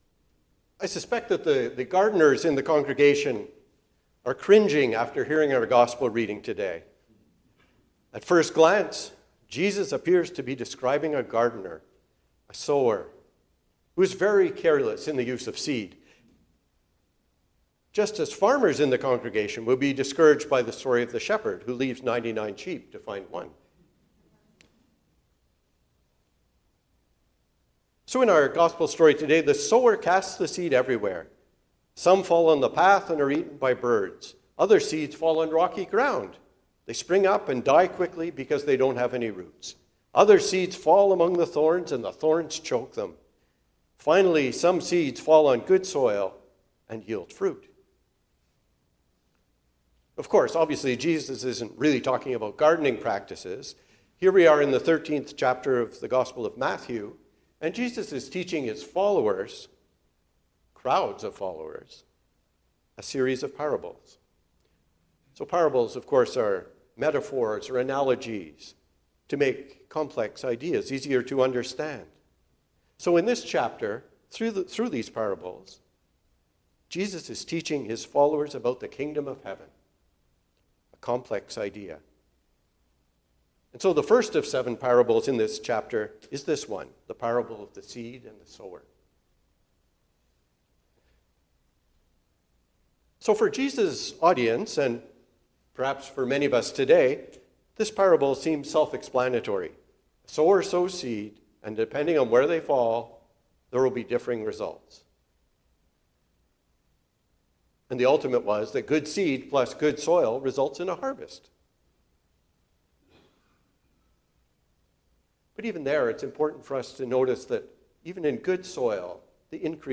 A Sermon for the Seventh Sunday after Pentecost – St. Helen's Anglican Church